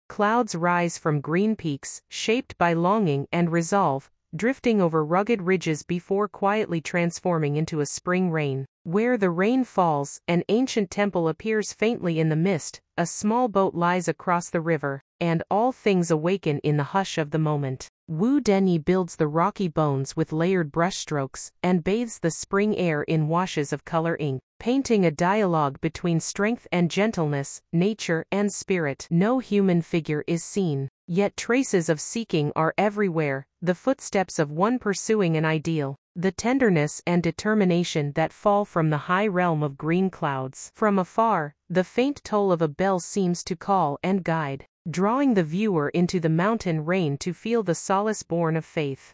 英文語音導覽